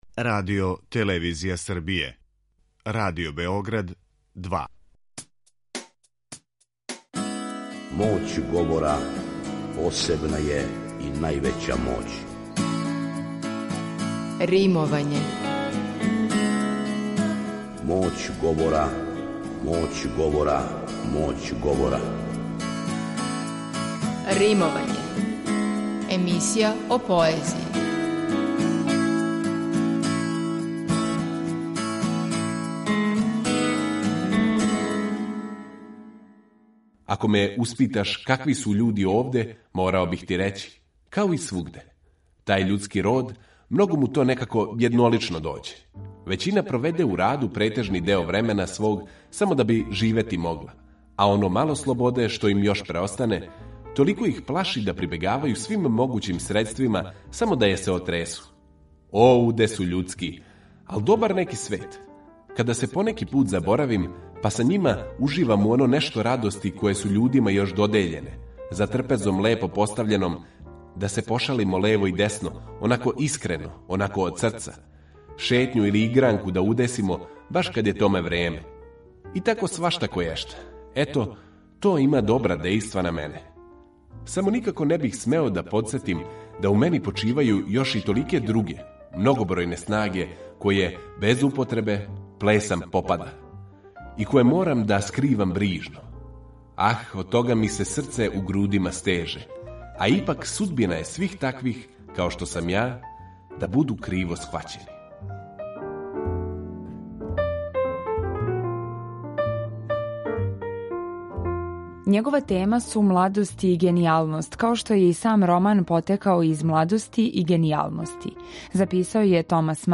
Слушаћемо одломке из његовог „Фауста", романа „Јади младог Вертера", подсетити се кључних места његове стваралачке биографије, али пре свега, слушаћемо његову поезију.